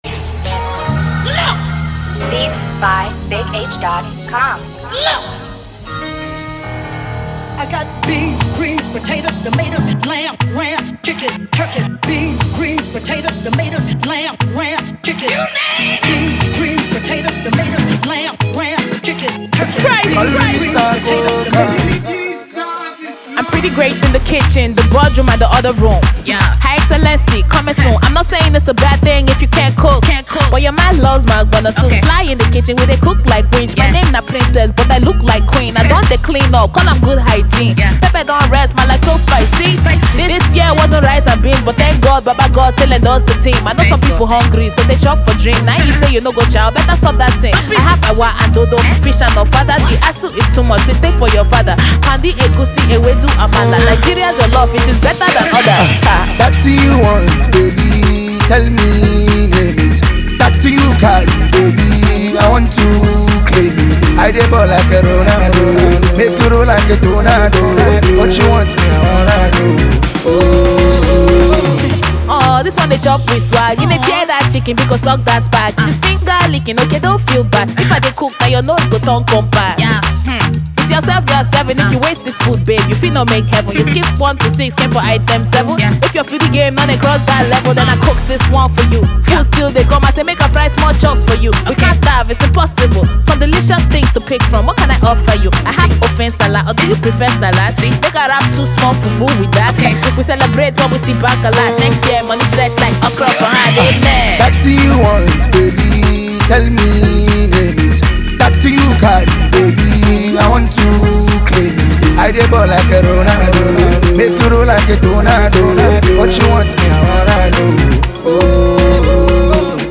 Afro-beat remix